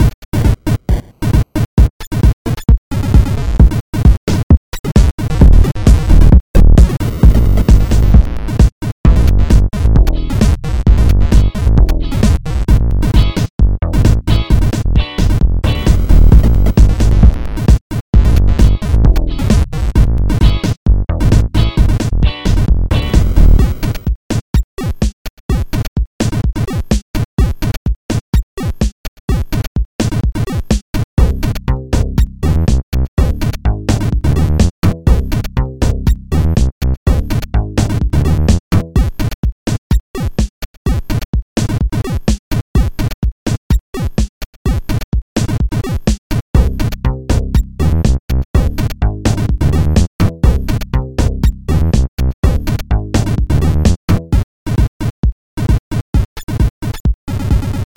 amped up video game stuff